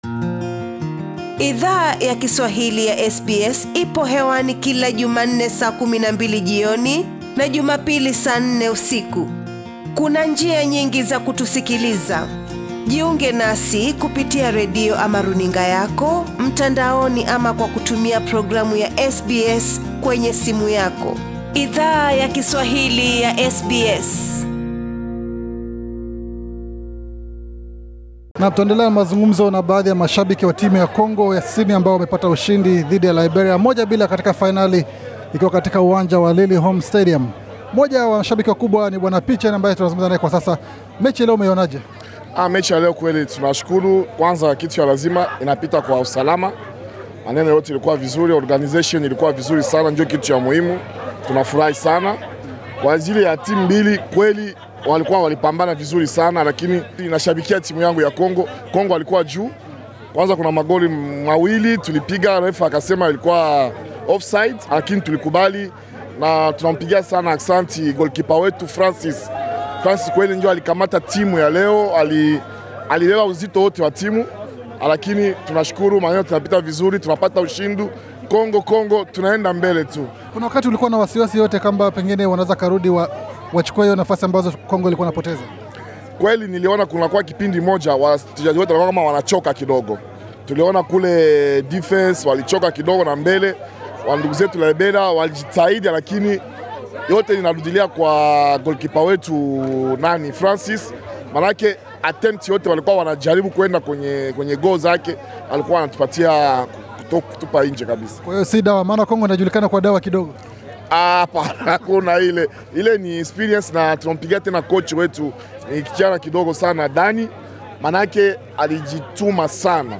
Wachezaji wa timu ya soka ya DR Congo wa NSW wamekuwa marafiki wa ukame wa ushindi, katika kombe la ANSA kwa muda mrefu. SBS Swahili ilihudhuria fainali yakusisimua ya kombe hilo, ambako tulizungumza na mashabiki pamoja na waandalizi wa kombe hilo.